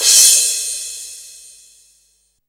43_11_cymbal.wav